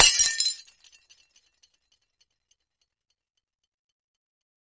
Kitchenware
Kitchen Glass Type 3 Crash